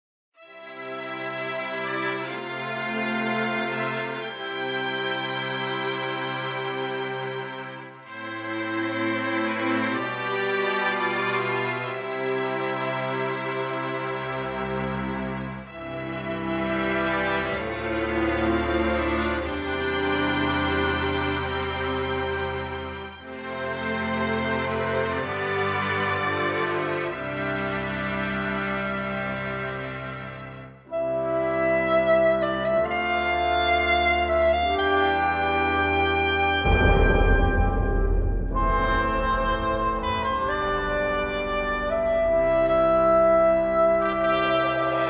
このアルバムで初のコーラスのレコーディングを行っている